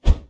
Close Combat Swing Sound 83.wav